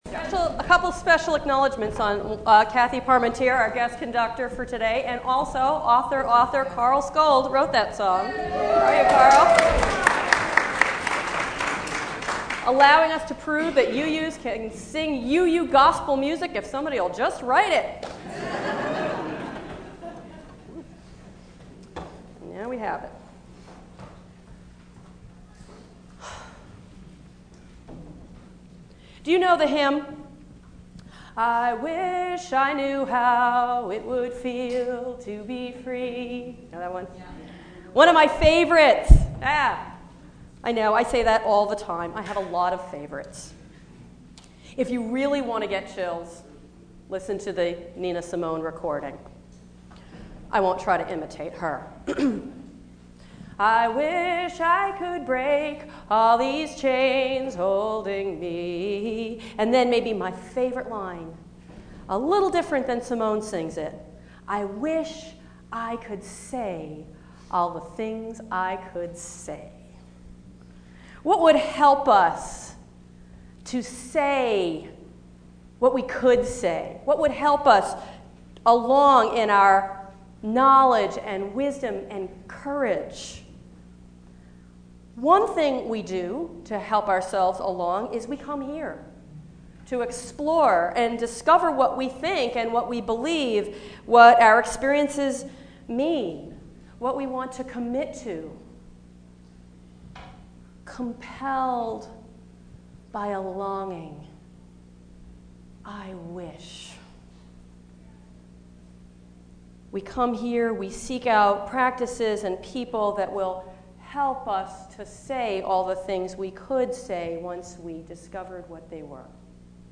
I’m posting audio of my sermons as often as possible from now on.